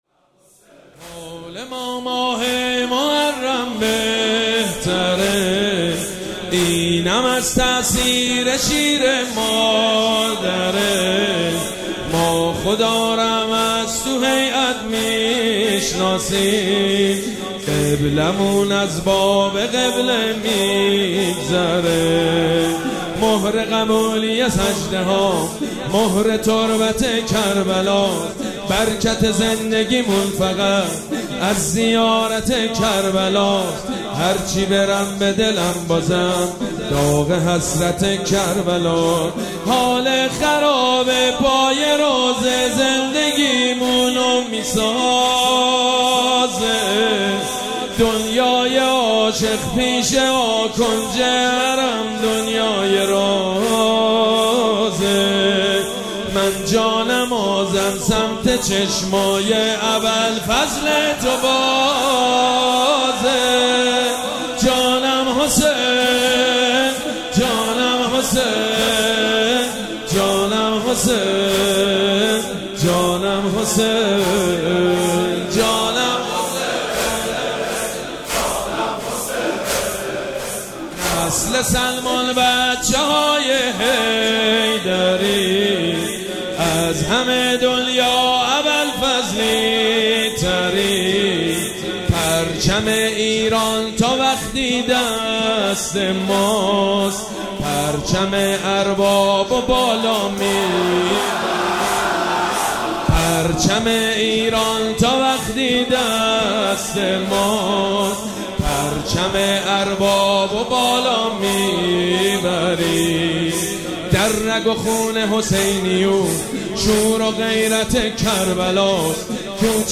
مراسم عزاداری شب دوم محرم الحرام ۱۴۴۷
مداح
حاج سید مجید بنی فاطمه